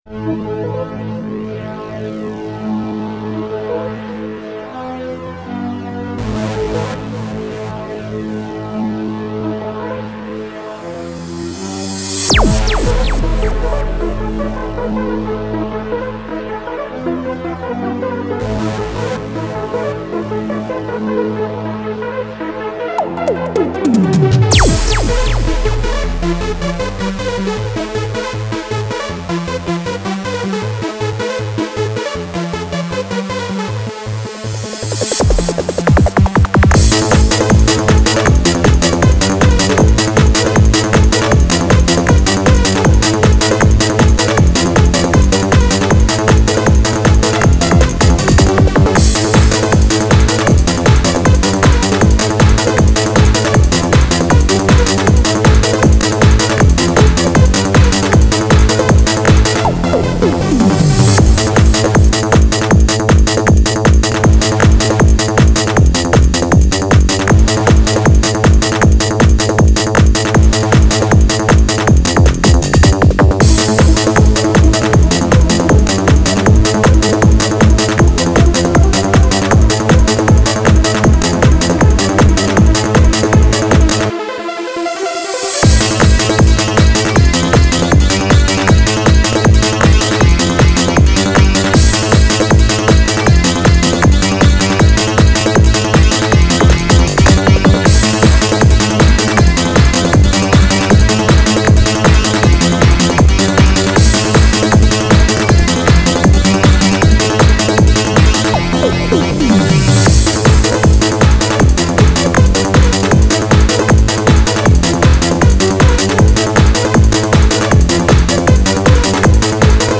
Мой_Ремикс